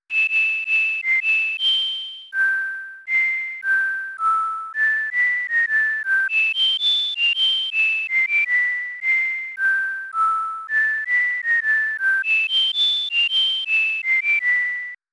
Sound effect from Super Mario RPG: Legend of the Seven Stars
SMRPG_SFX_Marrymore_Whistle.mp3